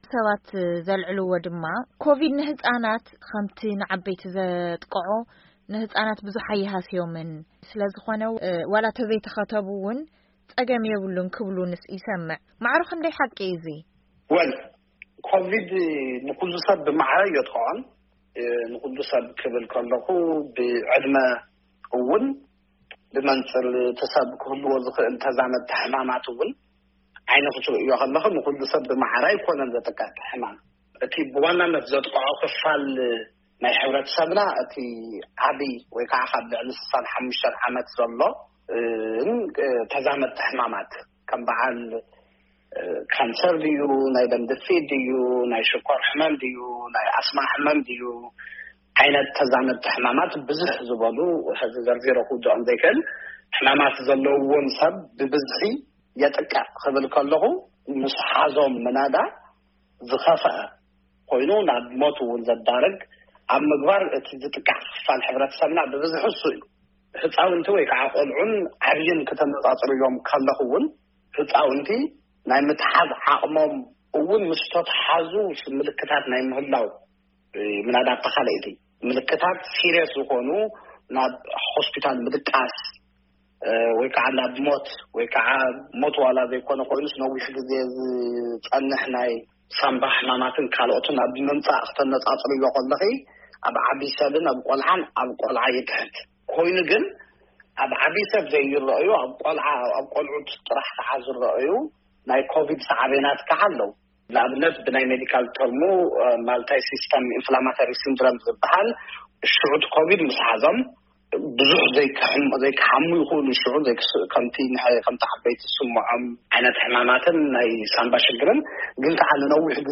ቃለ መሕትት ኣብ ጉዳይ ክታበት ቫይረስኮሮና ንህጻናት